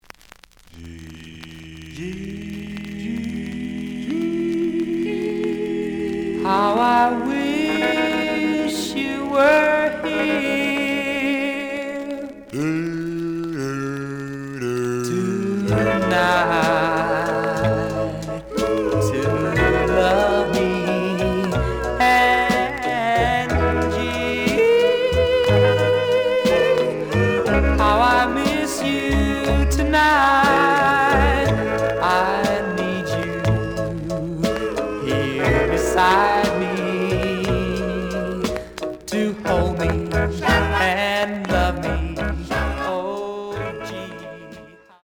The audio sample is recorded from the actual item.
●Genre: Rhythm And Blues / Rock 'n' Roll
Some click noise on B side due to scratches.